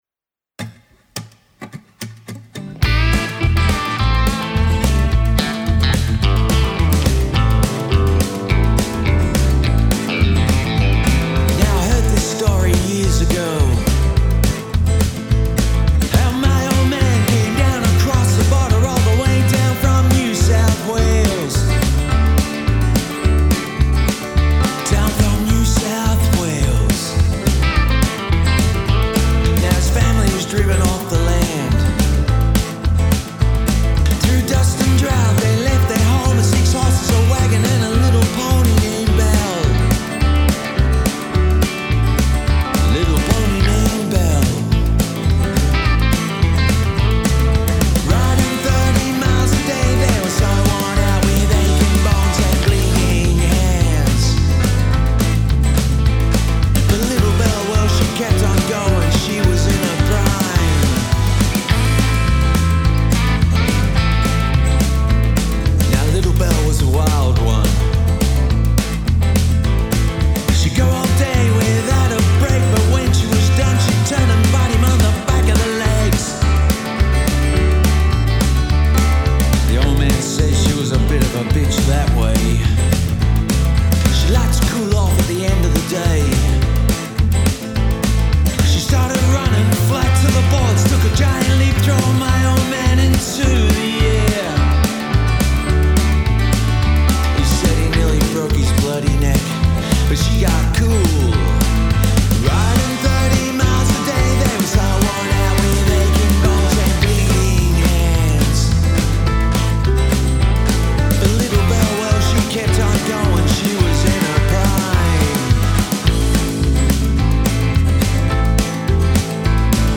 Lead Vocals, Bass
Acoustic Guitar, Piano, Background Vocals
Electric and Baritone Guitars
Drums